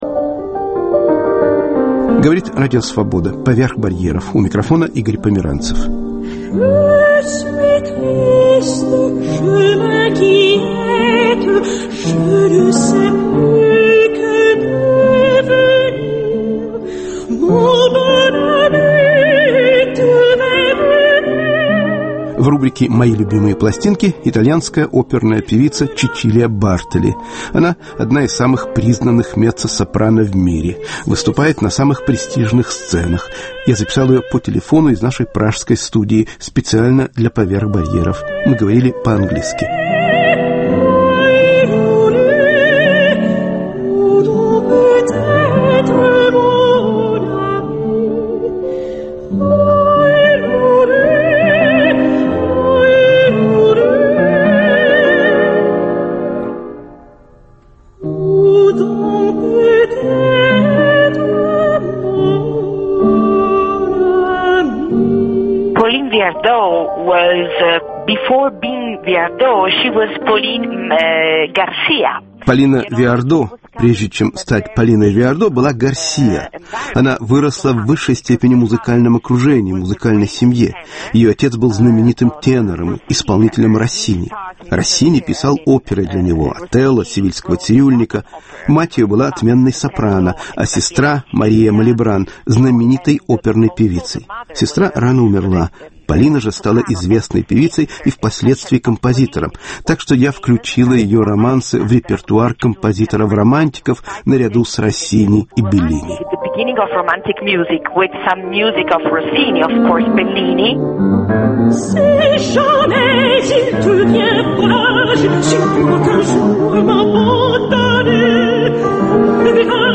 "Мои любимые пластинки" с меццо-сопрано Чечилией Бартоли.